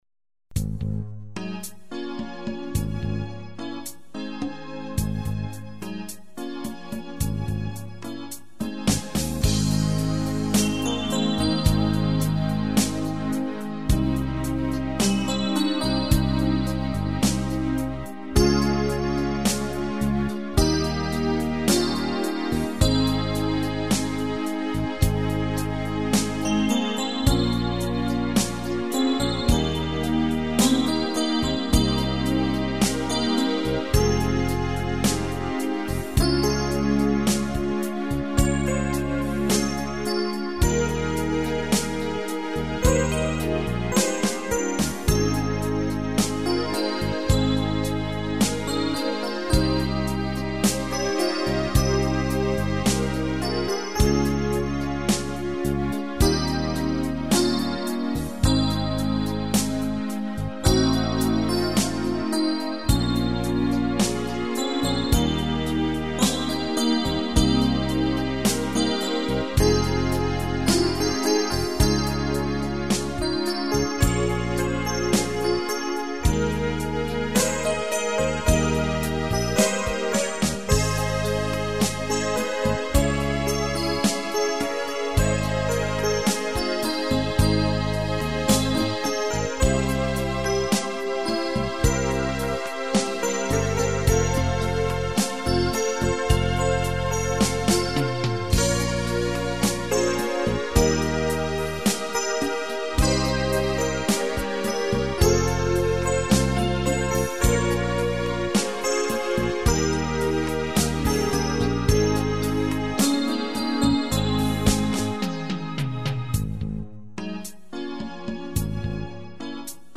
Playbacks-KARAOKE Zobrazení